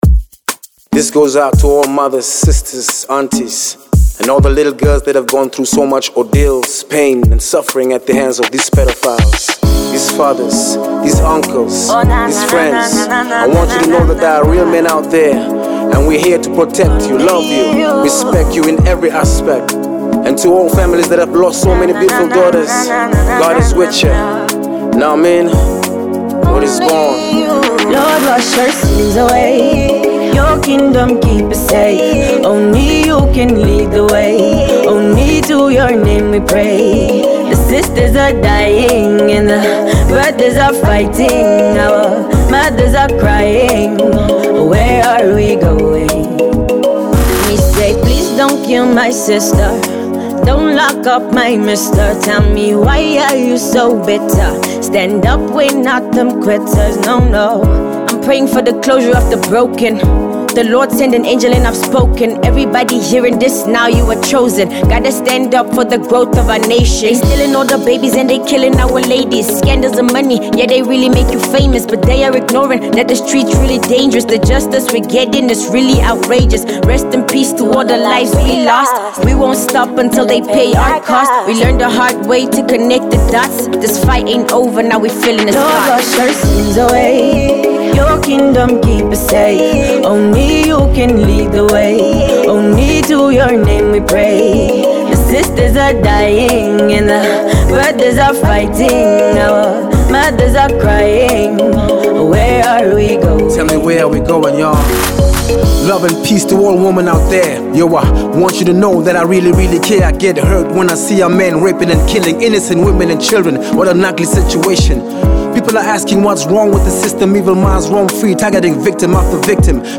a blend of dancehall, hip hop, and Afro-fusion